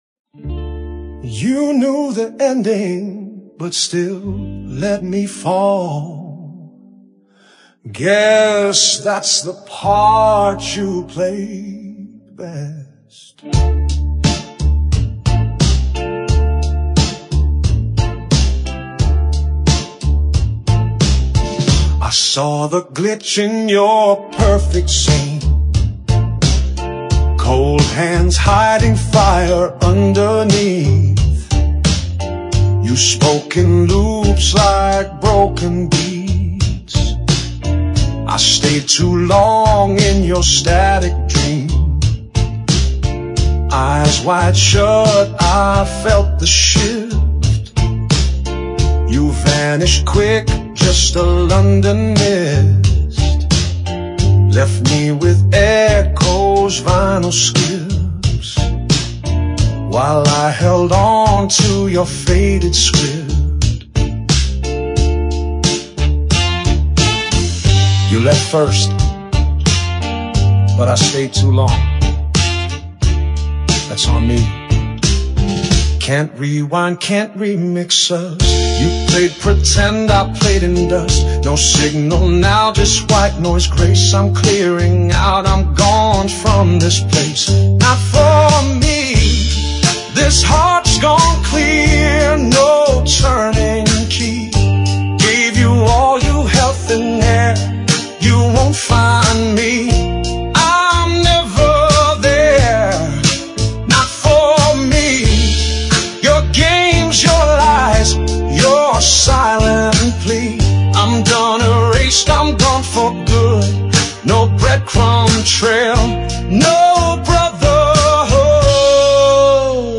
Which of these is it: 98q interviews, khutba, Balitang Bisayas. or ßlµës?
ßlµës